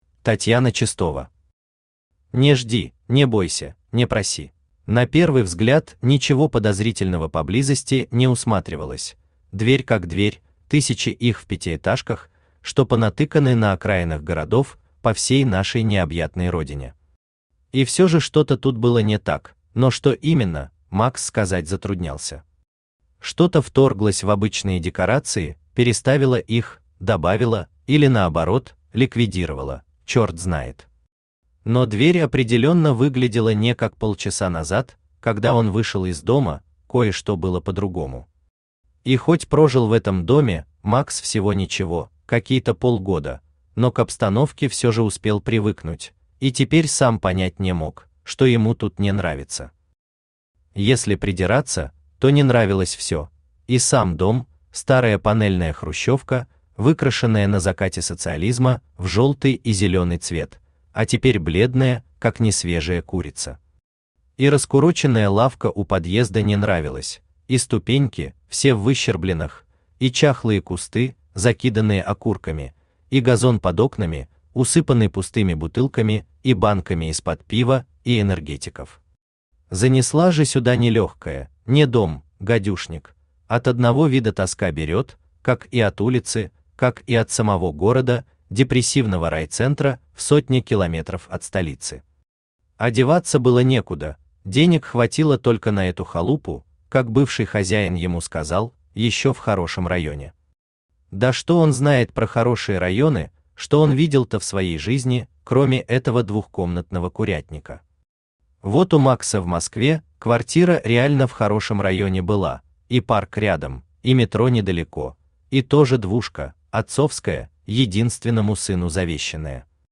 Aудиокнига Не жди, не бойся, не проси Автор Татьяна Чистова Читает аудиокнигу Авточтец ЛитРес.